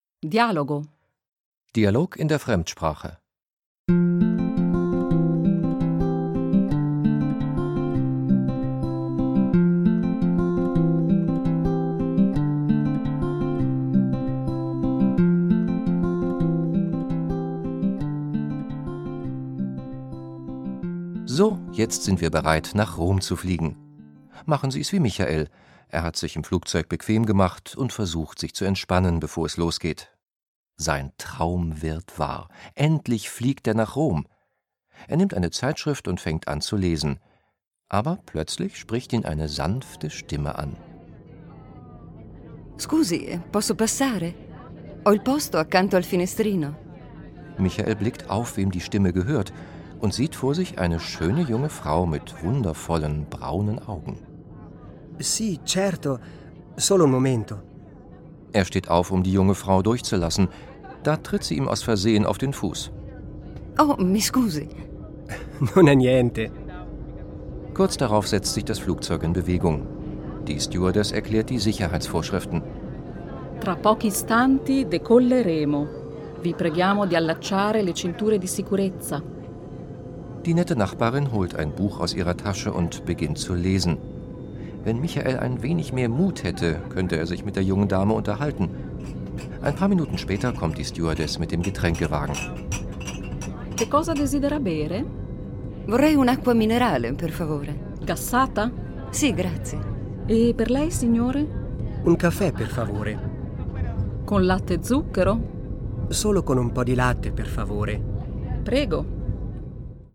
Der Sprachkurs zum Hören mit 4 Audio-CDs und Begleitbuch
Audio/Hörbuch
• Vier Stunden Hörvergnügen - professionell vertont von Muttersprachlern